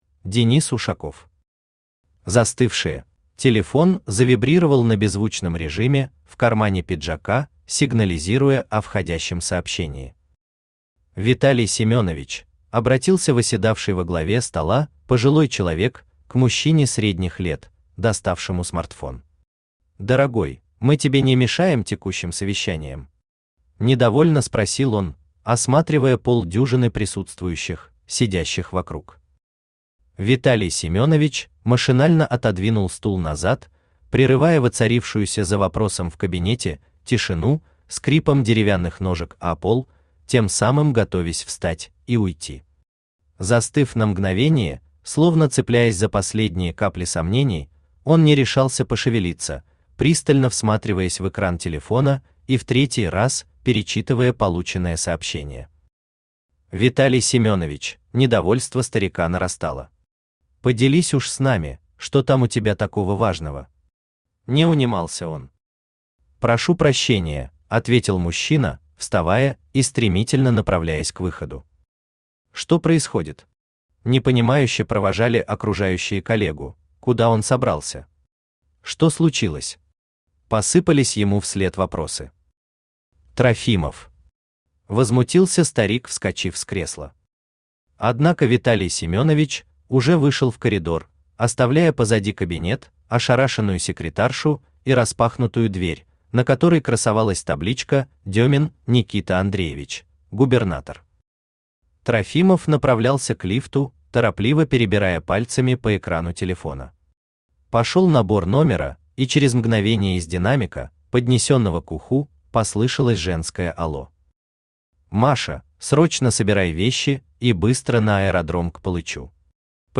Аудиокнига Застывшие | Библиотека аудиокниг
Aудиокнига Застывшие Автор Денис Ушаков Читает аудиокнигу Авточтец ЛитРес.